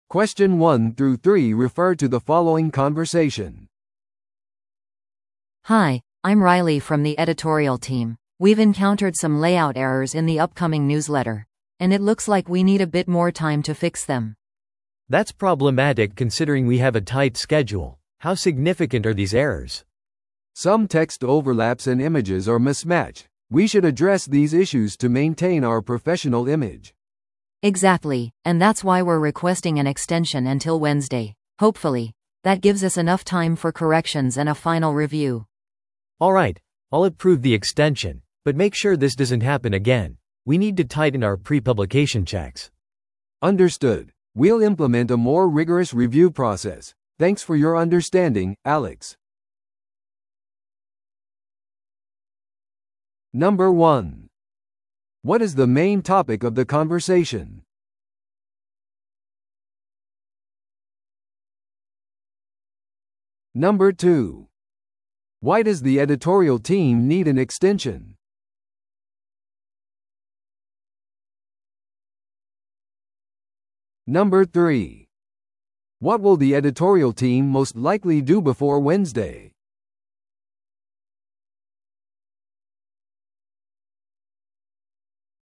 No.1. What is the main topic of the conversation?